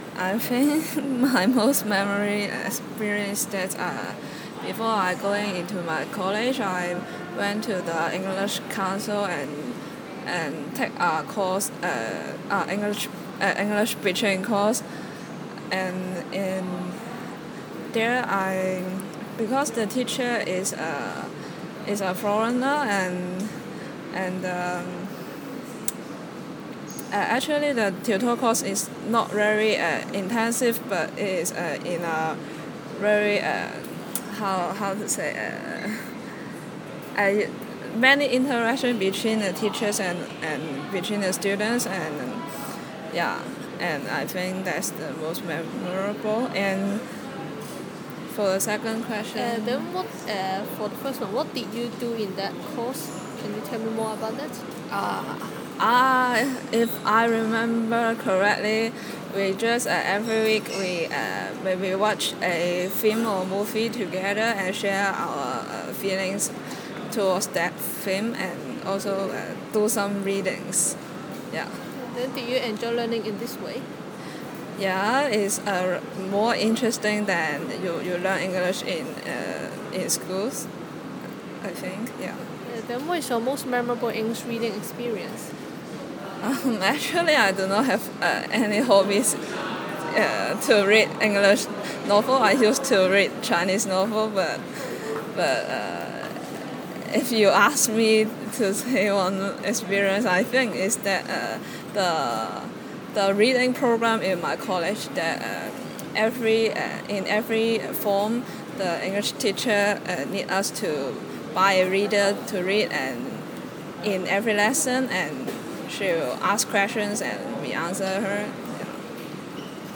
Subcategory: Fiction, Reading, Tutorial